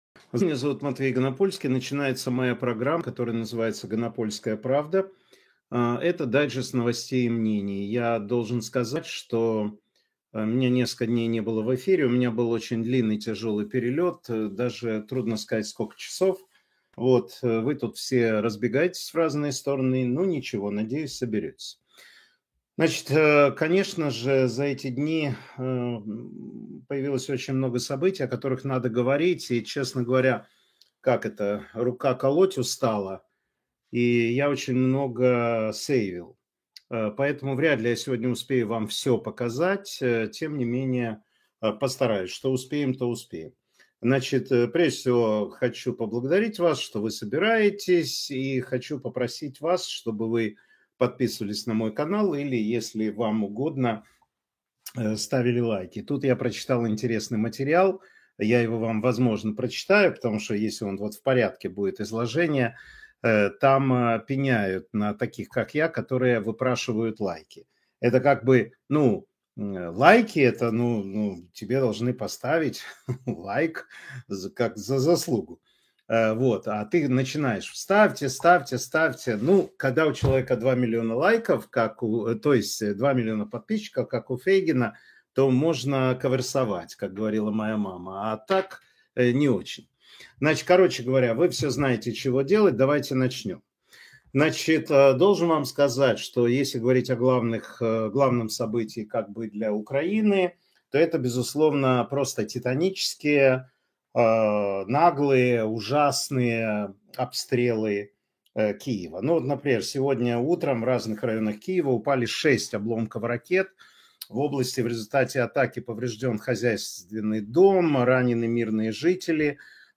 Эфир Матвея Ганапольского